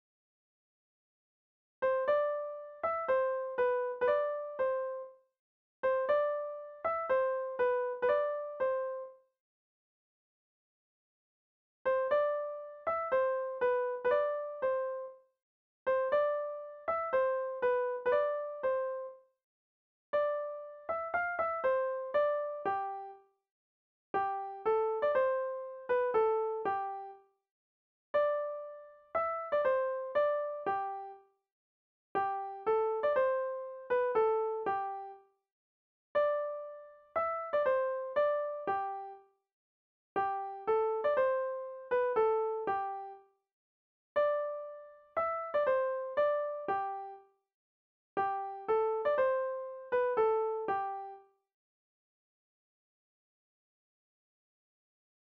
Genre laisse